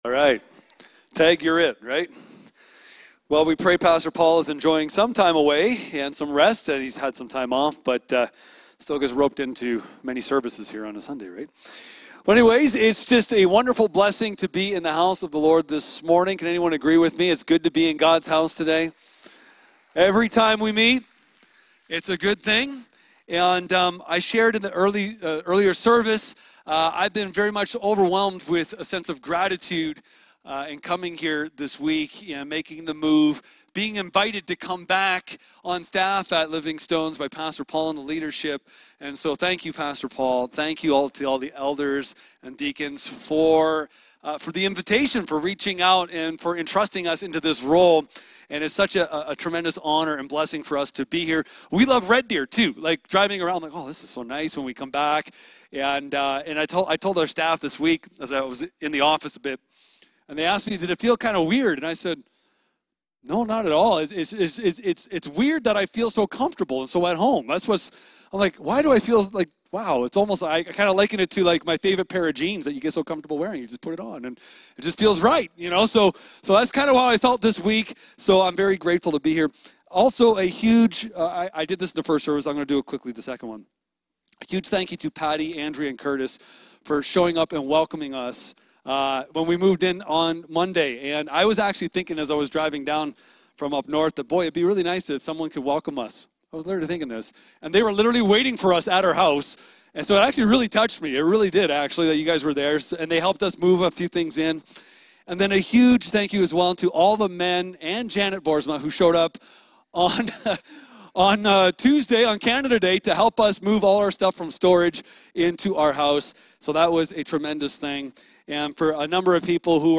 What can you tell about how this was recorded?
Living Stones Church, Red Deer, Alberta